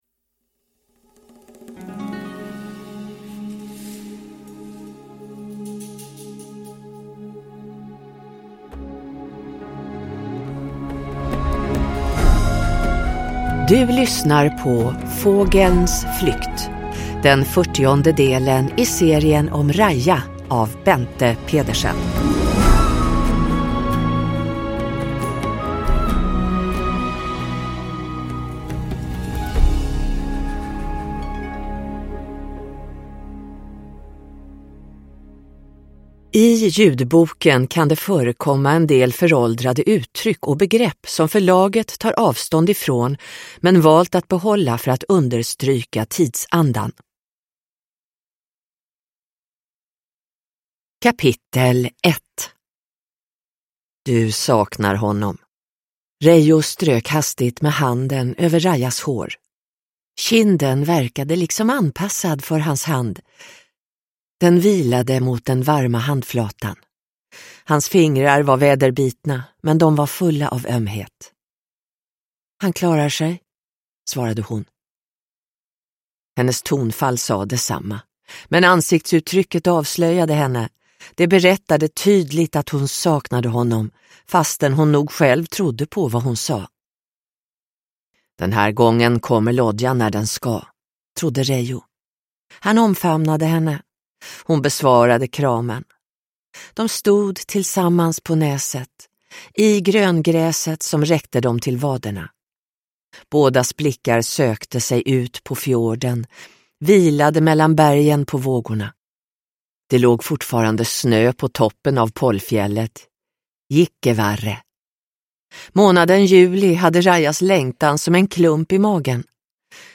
Fågelns flykt – Ljudbok – Laddas ner